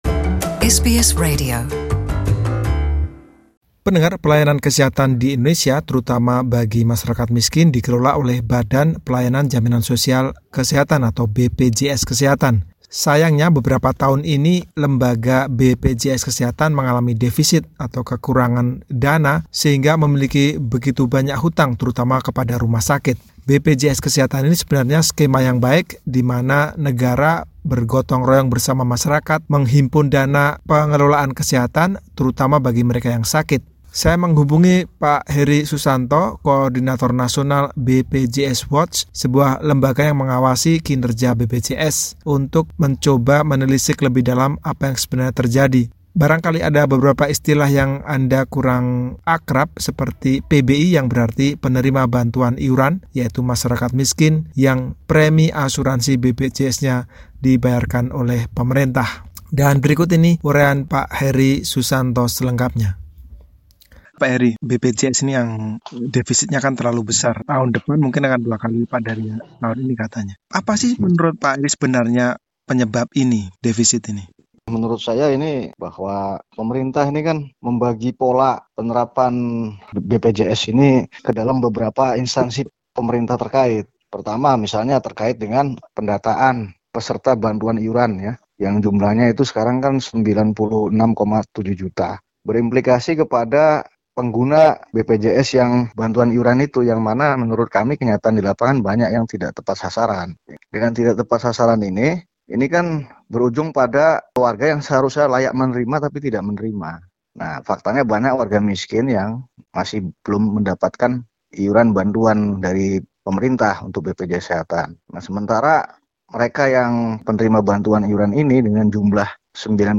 Berikut wawancara selengkapnya.